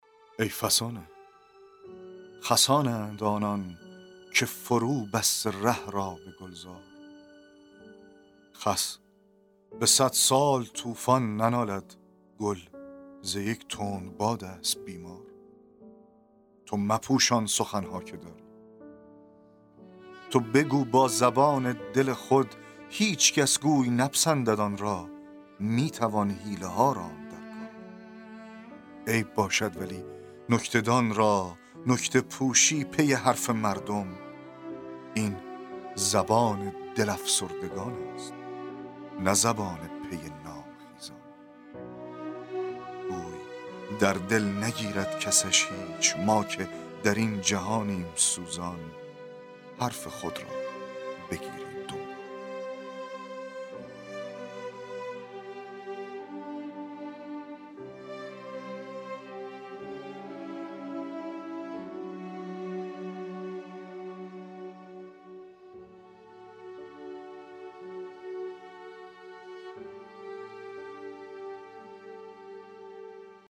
دکلمه و تحلیل شعر, شعرهای نیما یوشیج